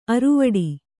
♪ aruvaḍi